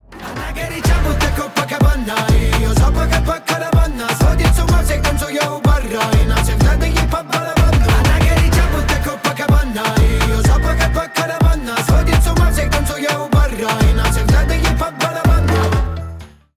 Танцевальные
ритмичные